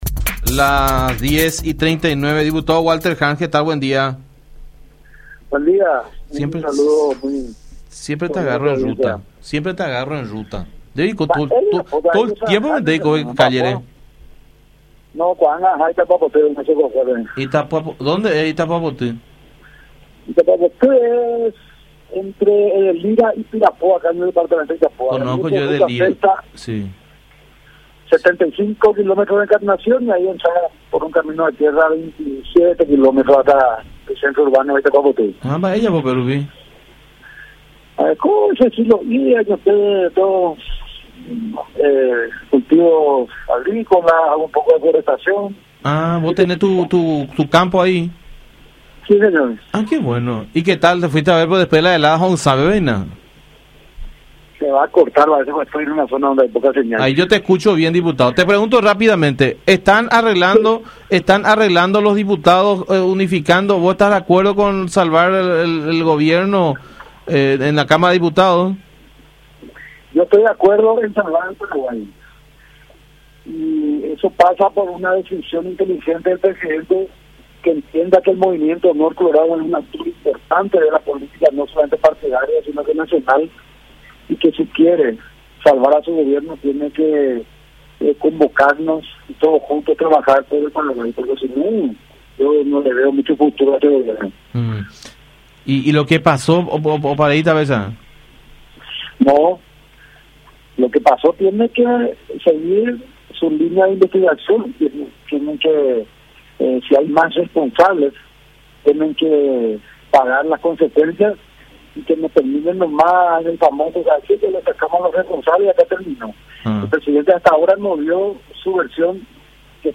“Si quiere salvar a su Gobierno, tiene que convocarnos para que todos juntos trabajemos por el Paraguay porque, o si no, yo no le veo mucho futuro”, dijo el diputado Harms, en contacto con La Unión, en referencia a la administración de Mario Abdo.
20-Walter-Harms-Diputado-Nacional.mp3